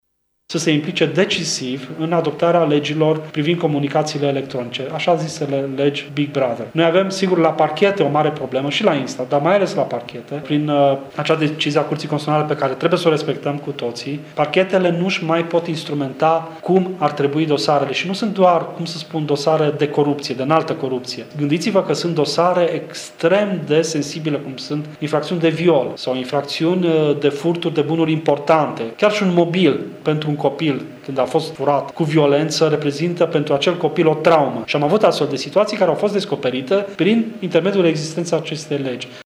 Nu în ultimul rând membrul CSM a arătat că trebuie tranşată problema legii Big Brother: